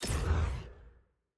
sfx-jfe-ui-roomselect-back.ogg